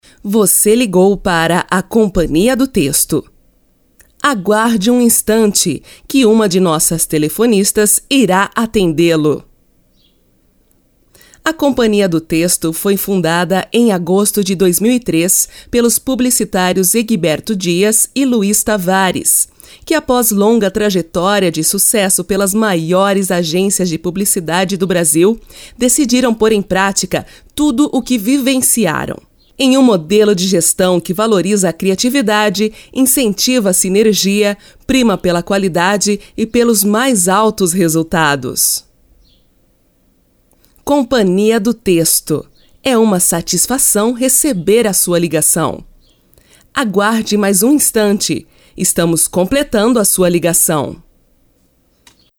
Je parle français avec un accent brésilien.
annonceuse brèsilienne, français avec accent brèsilien, home studio
Sprechprobe: Industrie (Muttersprache):